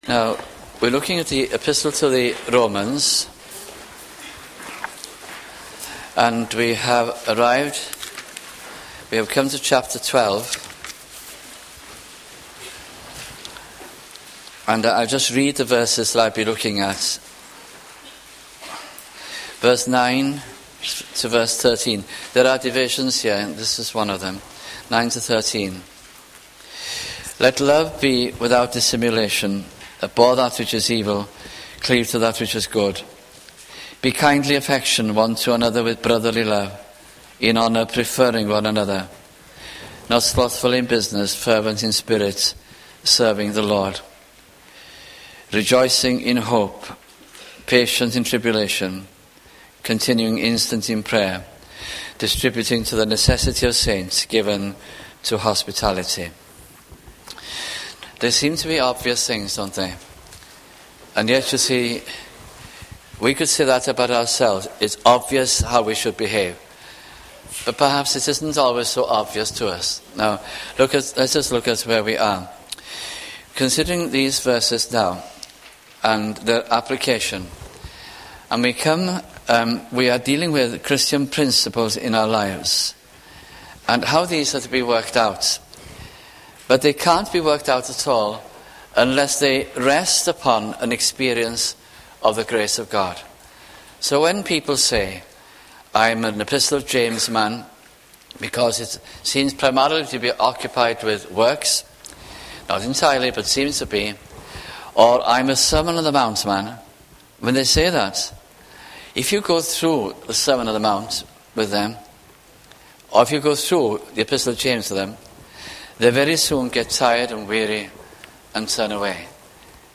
» Romans Series 1987 - 1988 » sunday morning messages